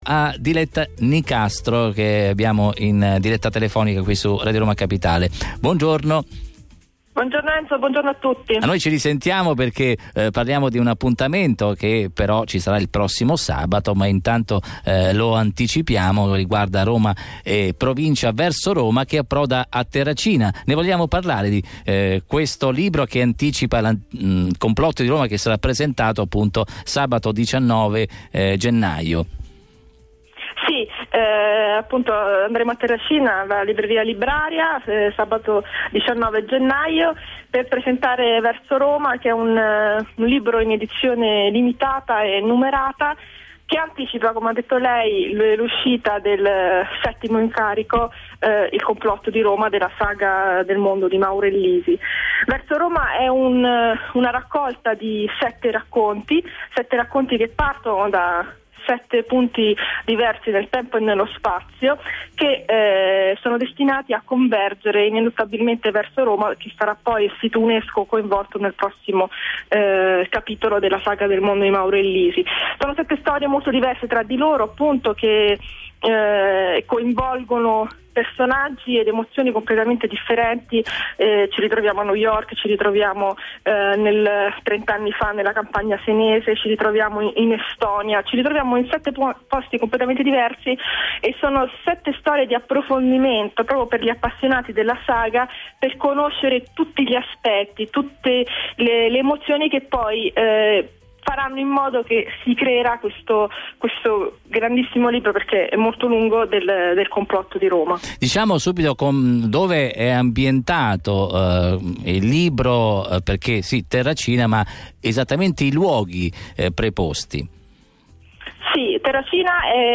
Talk a Radio Roma Capitale